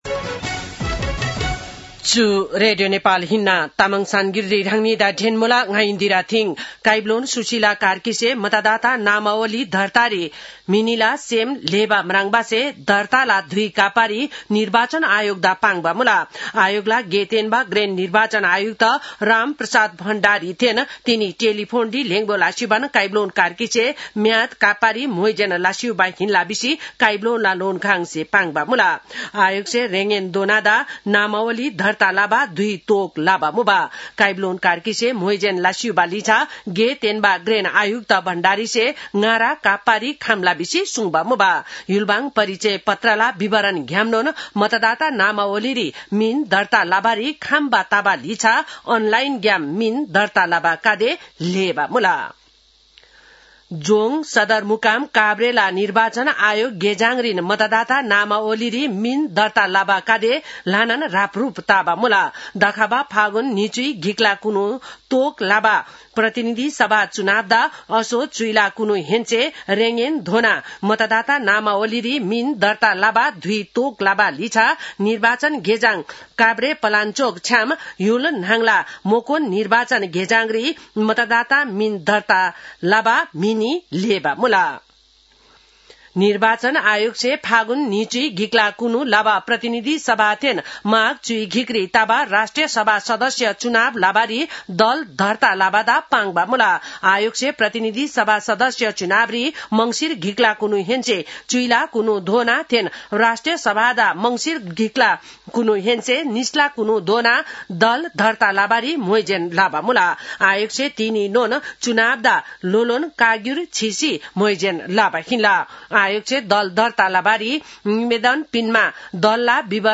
तामाङ भाषाको समाचार : २८ कार्तिक , २०८२
Tamang-news-7-28.mp3